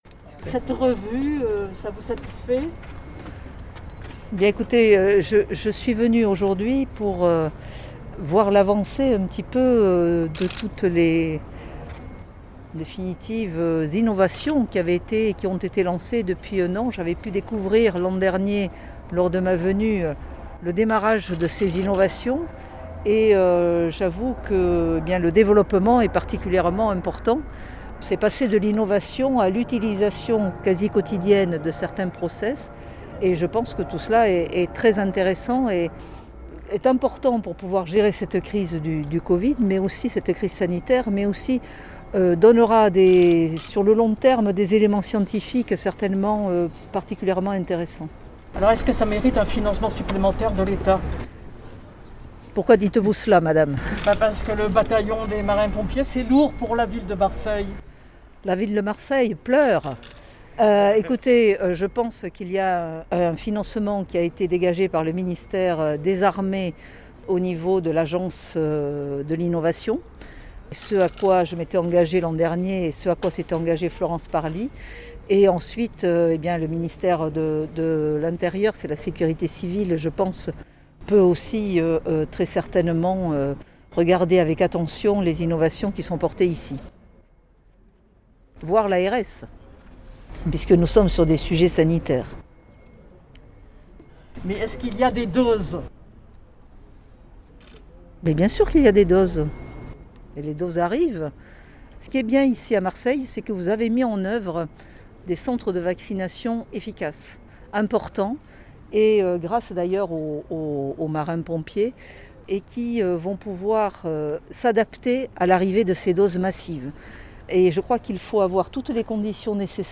A l’issue des ces visites Geneviève Darrieussecq a répondu aux questions de Destimed.